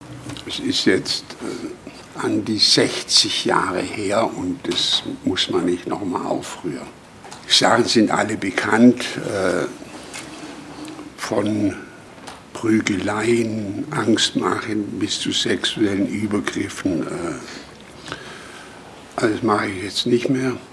Das sagte der Grünen-Politiker auf die Frage einer Journalistin am Rande der Landespressekonferenz am Dienstag in Stuttgart.